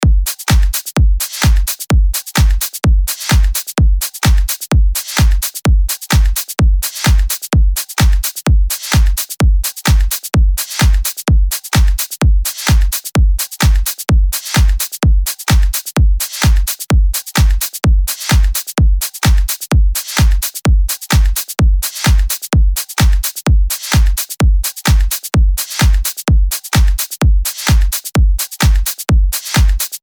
LP 117 – DRUM LOOP – EDM – 128BPM